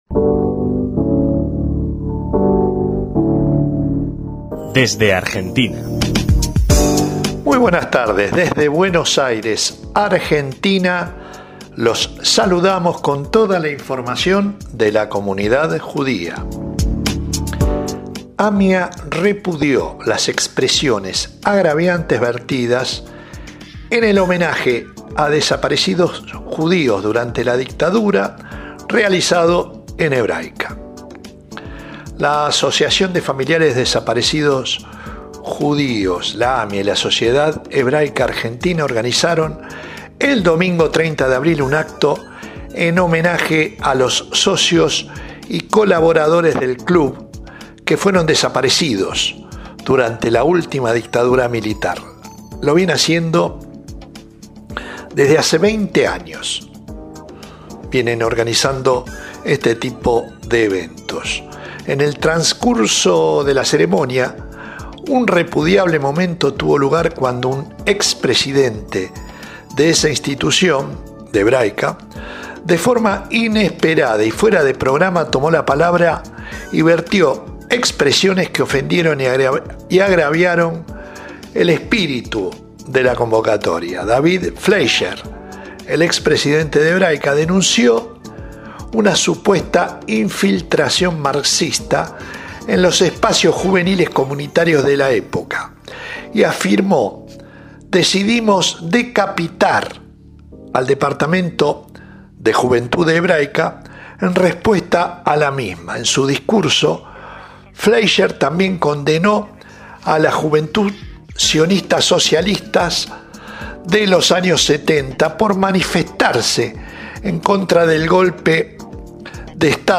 DESDE ARGENTINA, CON VIS A VIS - Nuevo informe bisemanal